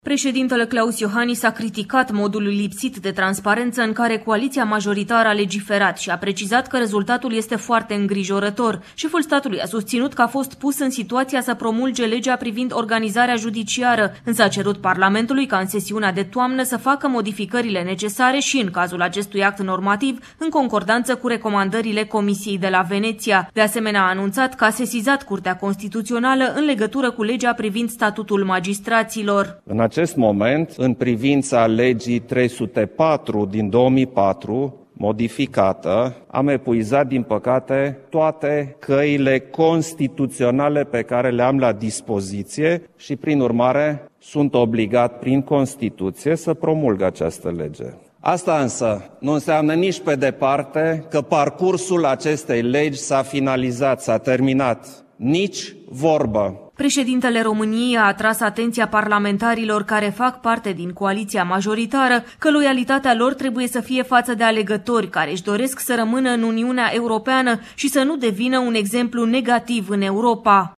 Preşedintele Klaus Iohannis sesizează Curtea Constituţională cu privire la modificările aduse în Parlament statutului judecătorilor şi procurorilor, dar anunţă că este obligat să promulge Legea 304 privind organizarea judiciară, după epuizarea căilor legale de atac. Într-o declaraţie de presă susţinută, ieri, la Palatul Cotroceni, şeful statului a făcut un apel la legislativ să reintroducă de urgenţă, în sesiunea de toamnă, legile justiţiei în circuitul parlamentar şi să le corecteze în acord cu recomandările Comisiei de la Veneţia.